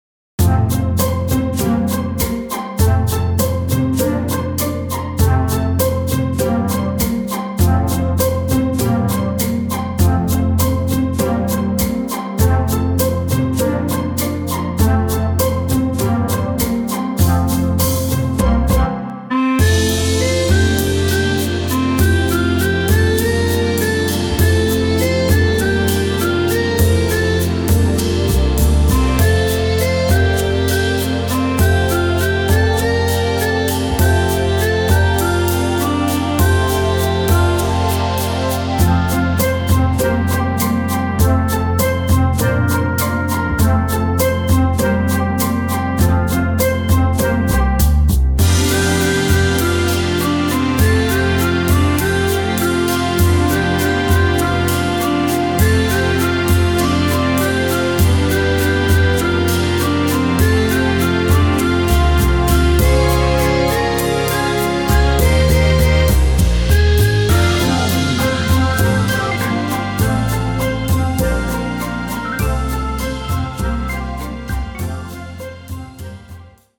Keyboards
Guitar, Bass